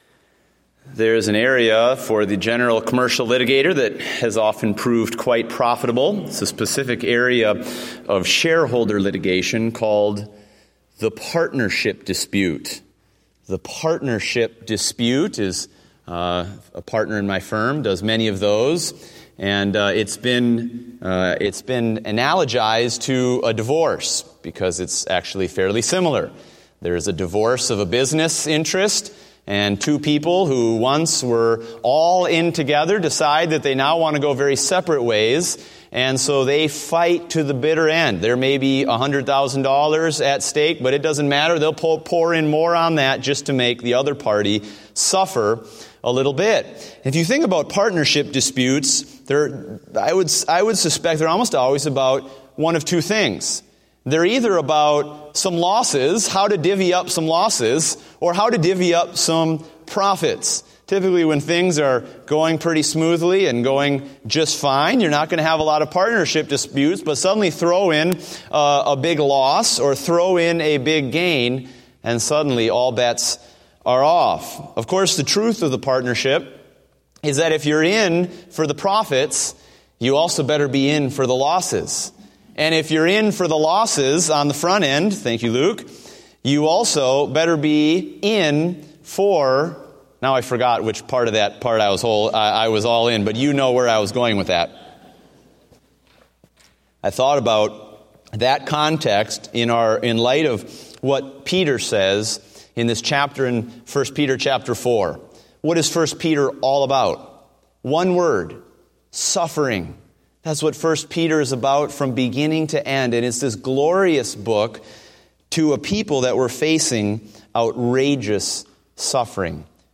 Date: October 18, 2015 (Evening Service)